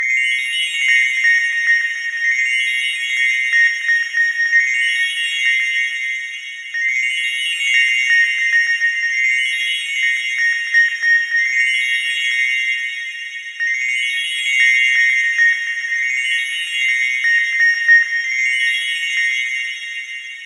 chimes synth chill summer delayed.wav
Original creative-commons licensed sounds for DJ's and music producers, recorded with high quality studio microphones.
chimes_synth_chill_summer_delayed_7ty.ogg